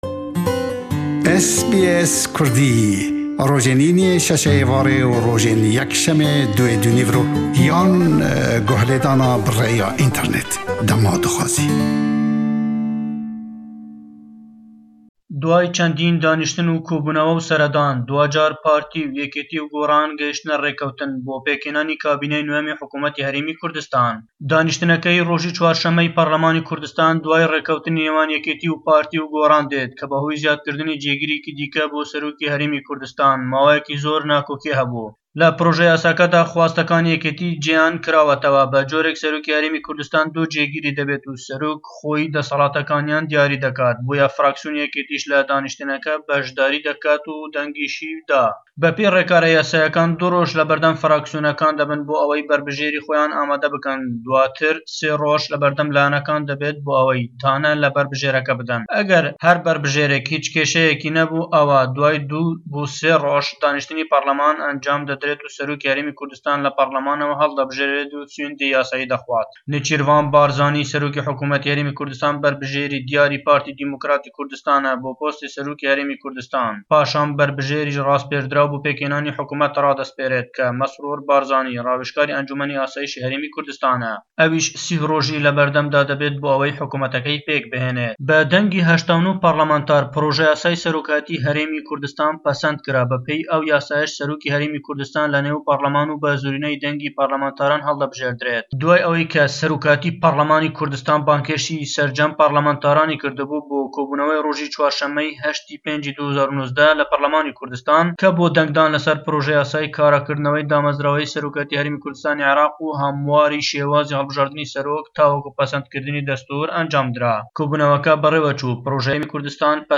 Raportî